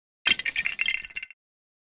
Lego Break Fall Apart Sound Effect Free Download
Lego Break Fall Apart